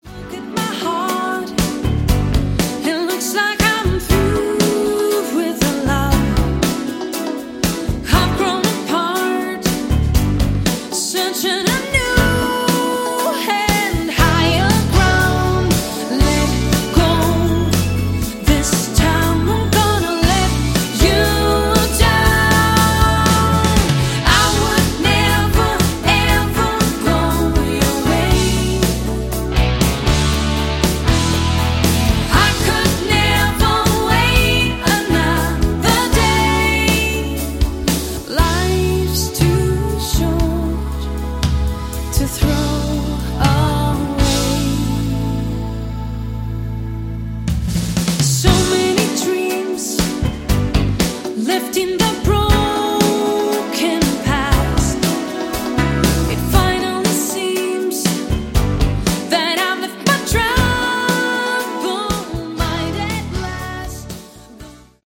Category: Westcoast AOR
lead vocals